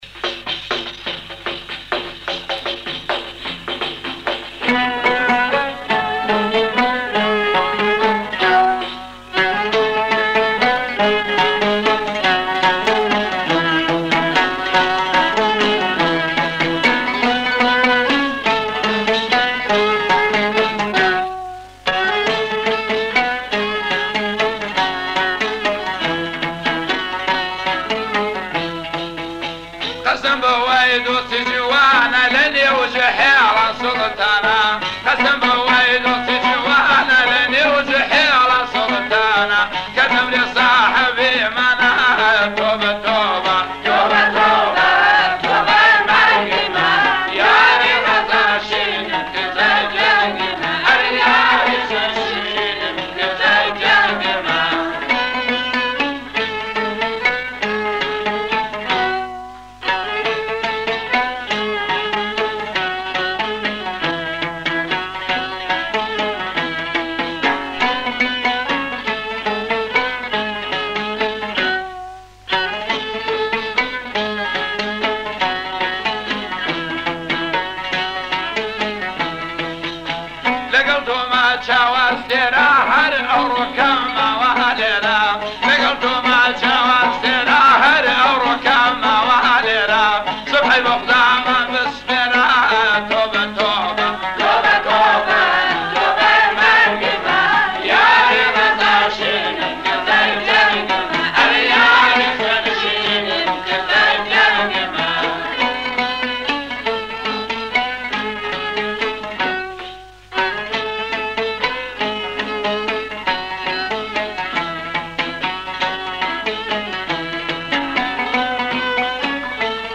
آهنگ کردی قدیمی